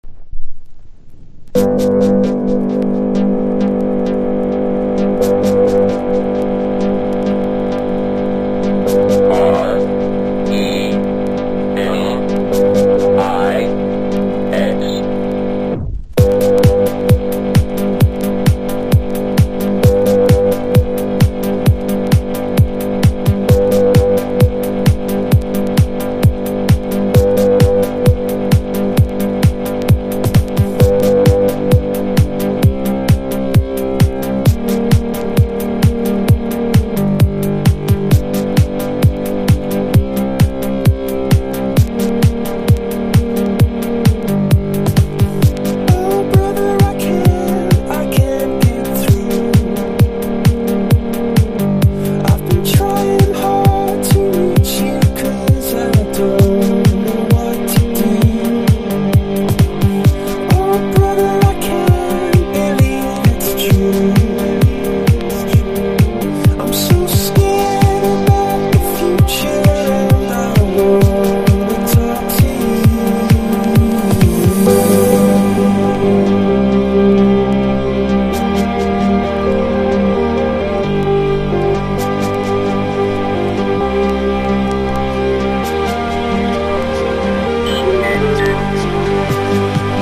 張りのあるキックを使ったロッキンなエレクトロに仕上がってます。
ELECTRO